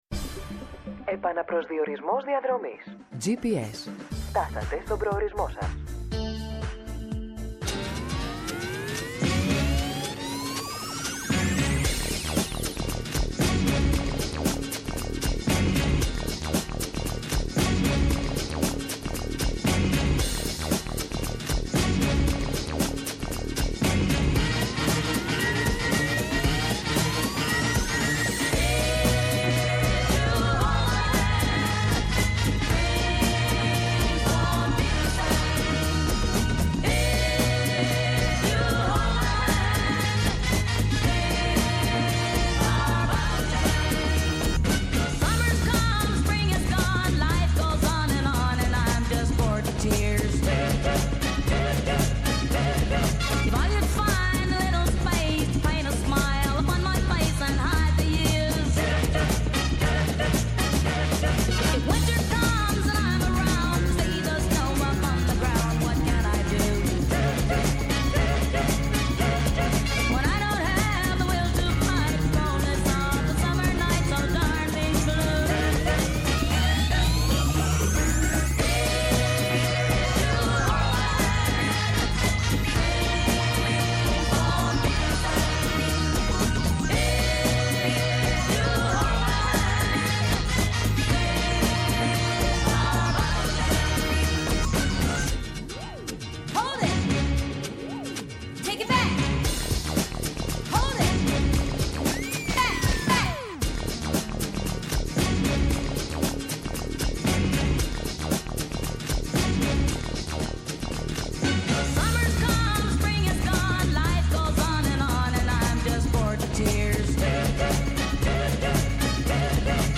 Eνα καθημερινό ραντεβού με τον παλμό της επικαιρότητας, αναδεικνύοντας το κοινωνικό στίγμα της ημέρας και τις αγωνίες των ακροατών μέσα από αποκαλυπτικές συνεντεύξεις και πλούσιο ρεπορτάζ επιχειρεί να δώσει η εκπομπή Gps